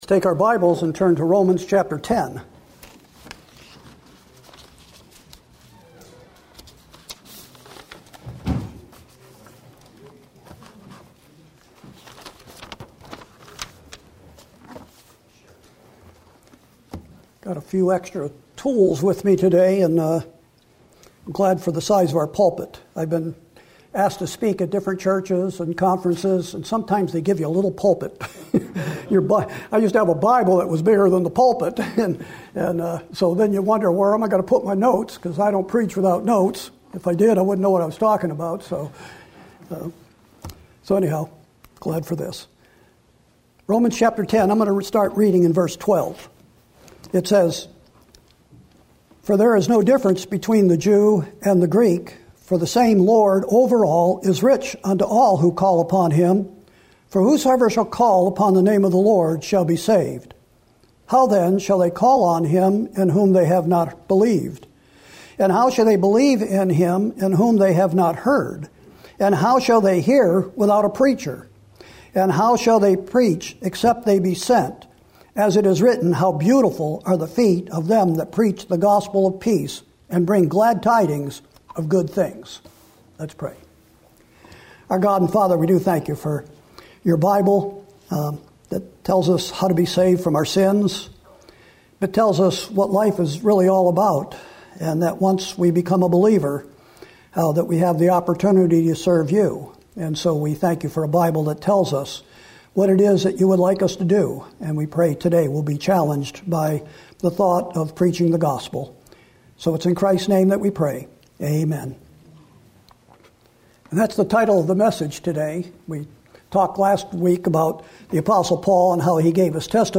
Sermons & Single Studies